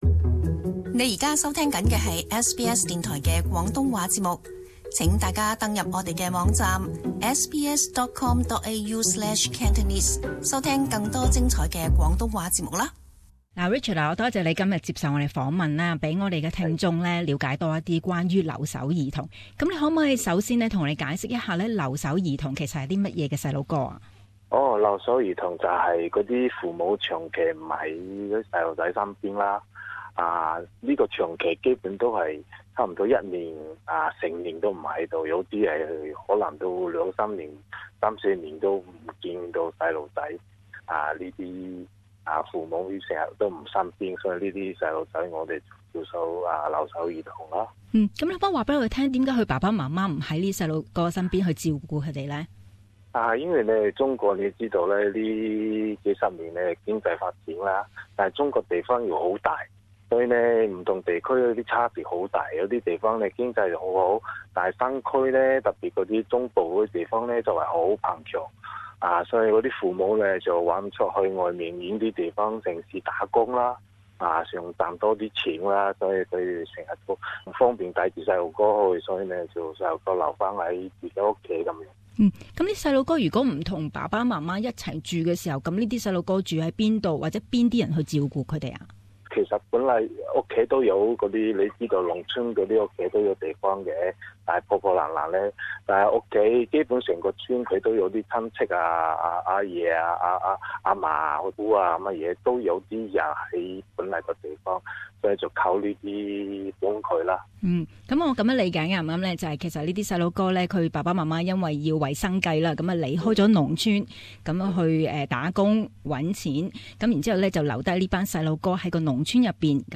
【社區專訪】留守兒童